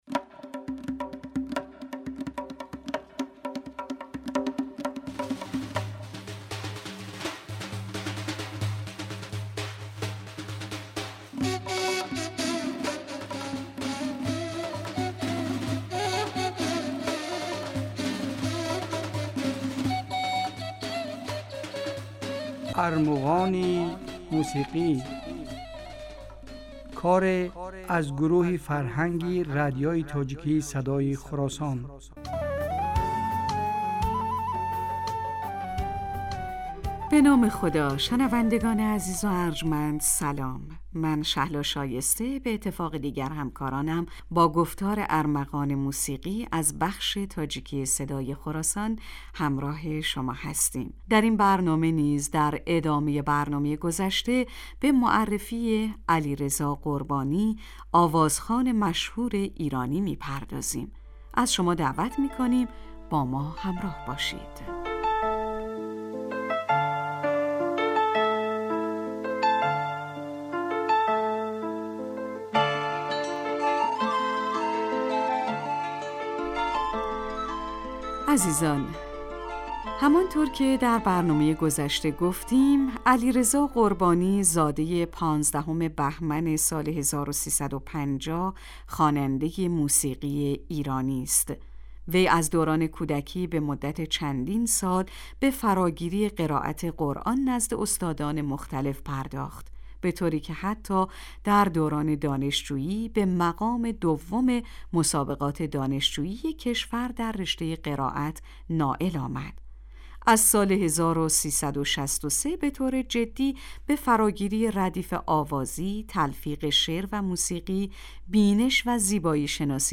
Армуғони мусиқӣ асари аз гурӯҳи фарҳанги радиои тоҷикии Садои Хуросон аст. Дар ин барномаҳо кӯшиш мекунем, ки беҳтарин ва зеботарин мусиқии тоҷикӣ ва эрониро ба шумо пешкаш кунем.